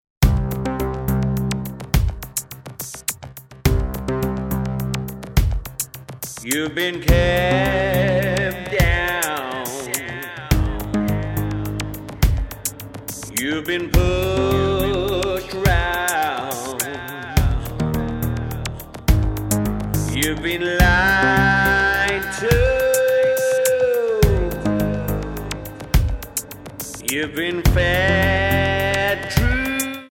Tonart:Cm Multifile (kein Sofortdownload.
Die besten Playbacks Instrumentals und Karaoke Versionen .